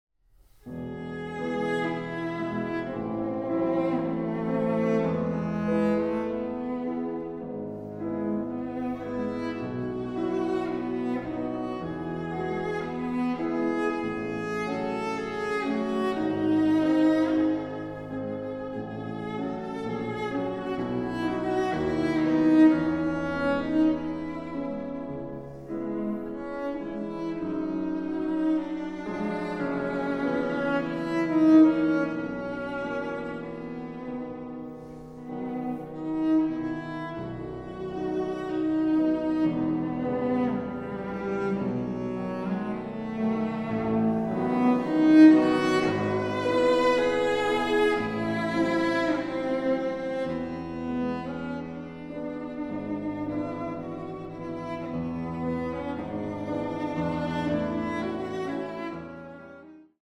Klavierquartett